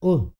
TUVANGROAN17.wav